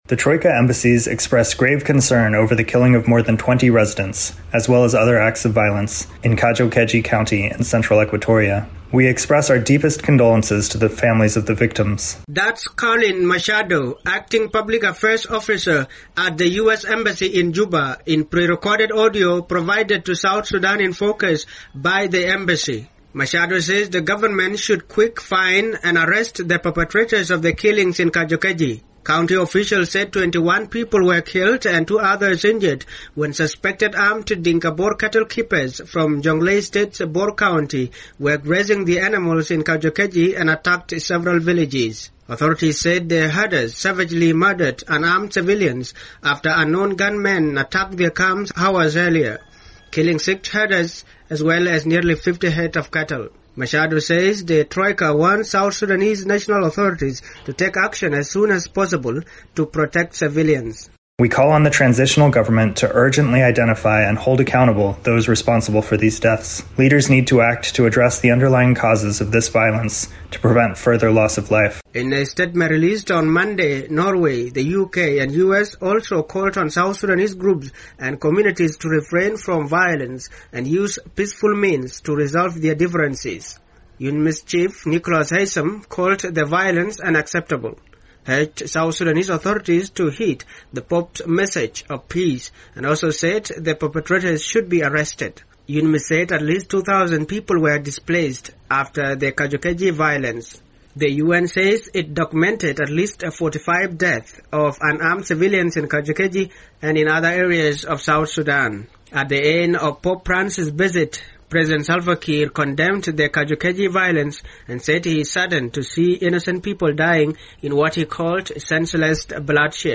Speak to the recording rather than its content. reports from Juba